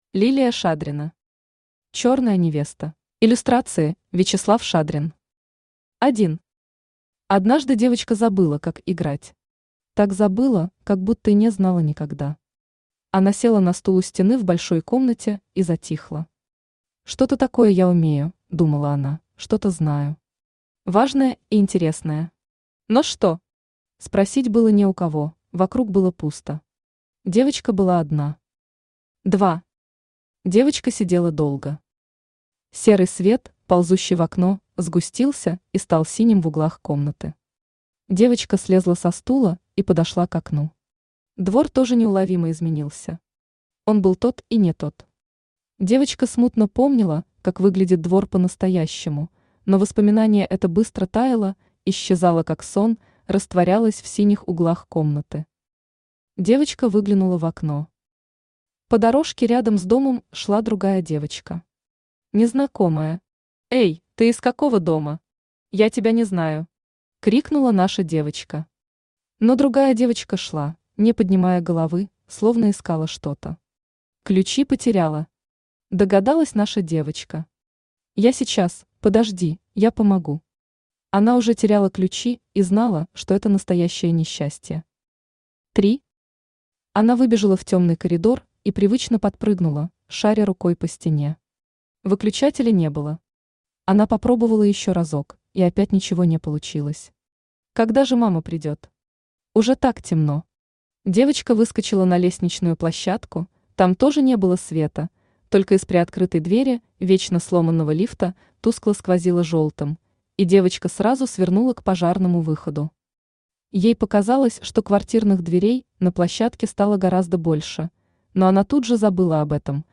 Аудиокнига Черная Невеста | Библиотека аудиокниг
Aудиокнига Черная Невеста Автор Лилия Шадрина Читает аудиокнигу Авточтец ЛитРес.